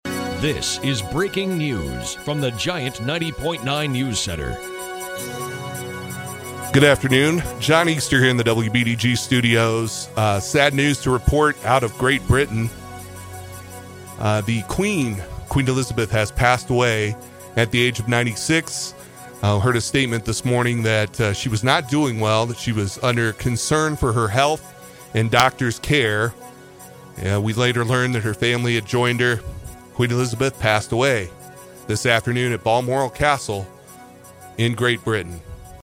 We hate to make breaking news announcements like this, but this was one we felt we had to do. Our condolences to Queen Elizabeth II's family and her subjects.